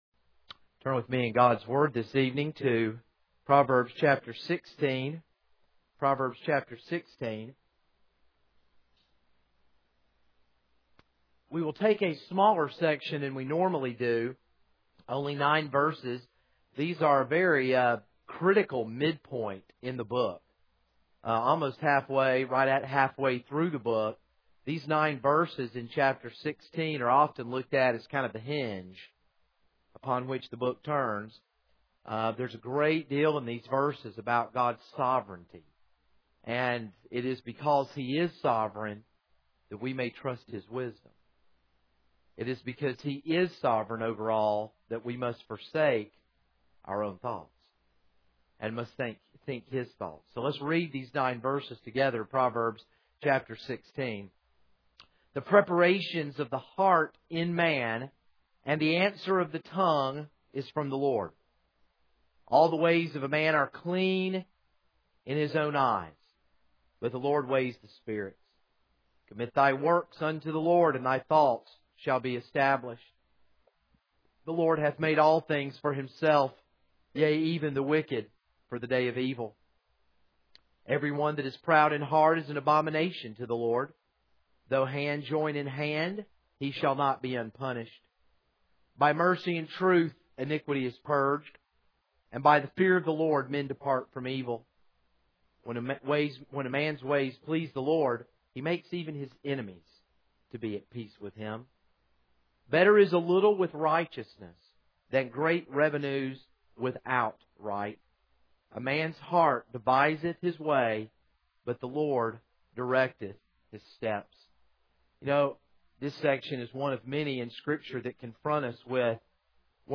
This is a sermon on Proverbs 16:1-9.